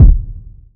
Miss Me Kick.wav